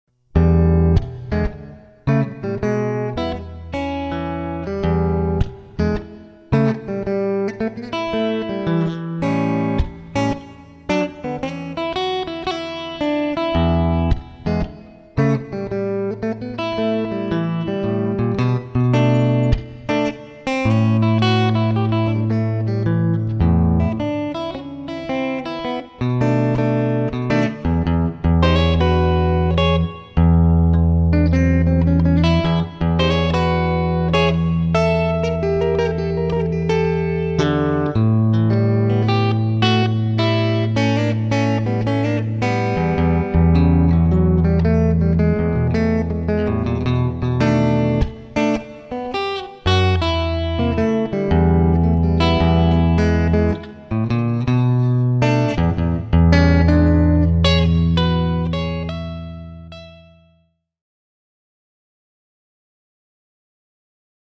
ぶるぅす
以前、録音していたブルースフレーズをエレキで録り直してみますた。
あとは、リズムの正確さとミュートが課題な気がする・・・・・。 ブルースフレーズ.mp3 使用楽器：Crews 52TL オーディオインターフェース：UA-3FX
blues.mp3